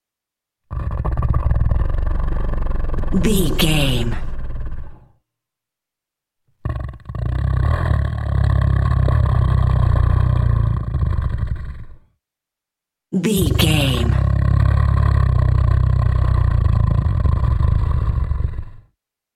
Monster sleep snore big creature x3
Sound Effects
scary
ominous
eerie